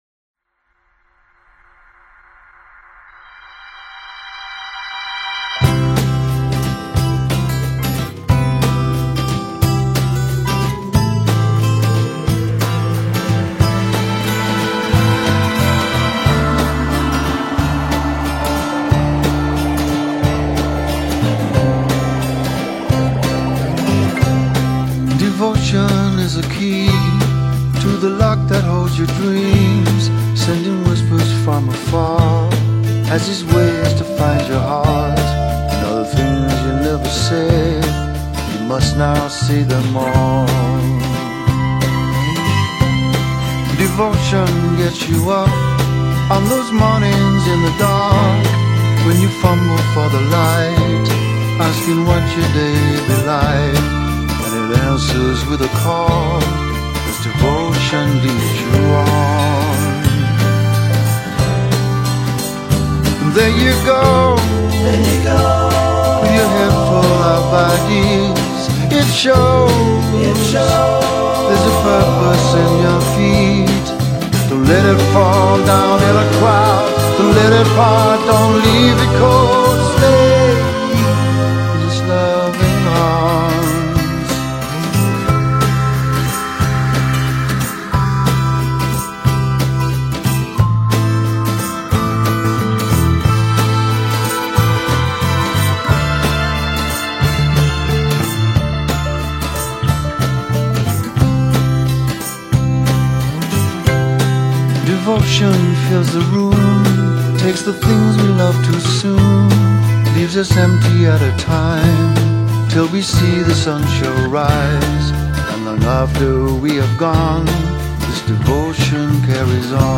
pastoral and sprightly